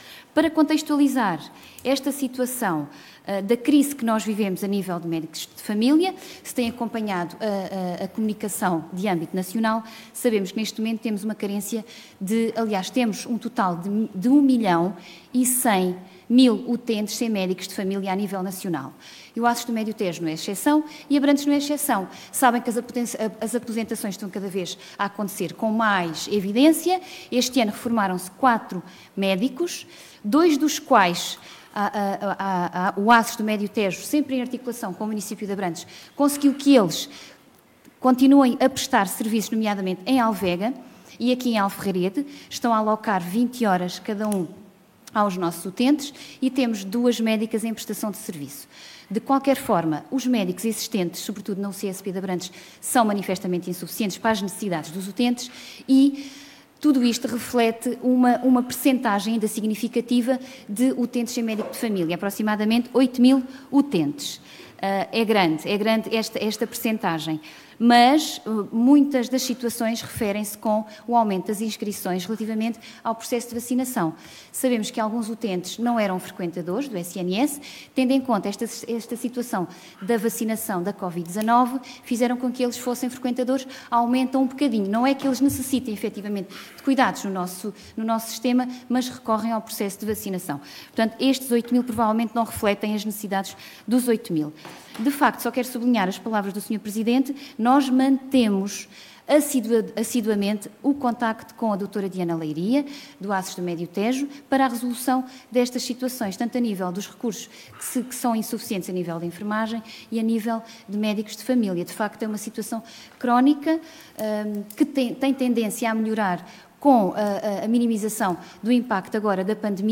“Este ano reformaram-se quatro médicos – dois dos quais o ACES do Médio Tejo em articulação com o Município de Abrantes conseguiu que continuassem a prestar serviços em Alvega e Alferrarede, estão a alocar 20 horas aos nossos utentes – e temos duas médicas em prestação de serviços”, disse a vereadora em sessão do executivo na terça-feira, na sequência de uma proposta relativa a cuidados de saúde primários apresentada pelo vereador Vasco Damas (ALTERNATIVAcom) – que acabou por ser chumbada.
ÁUDIO | Raquel Olhicas, vereadora da CM Abrantes: